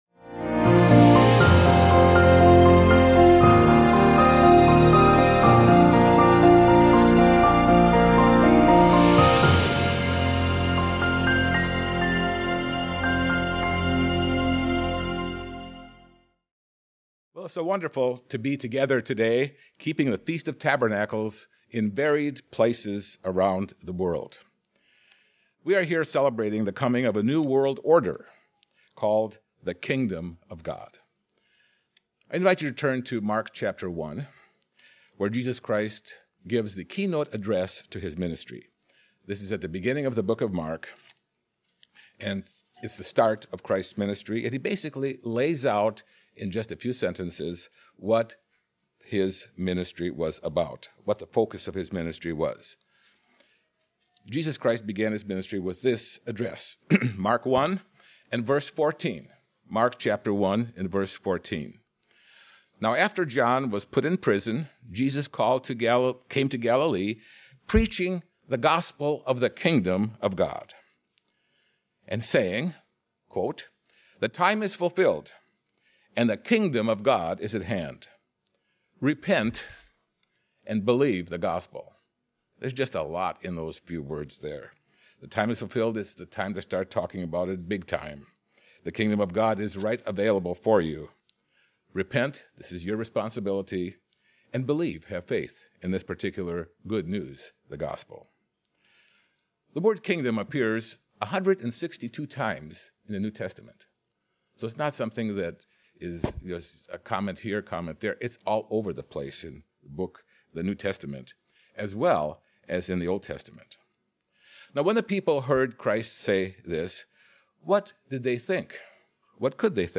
This sermon was given at the Panama City Beach, Florida 2020 Feast site.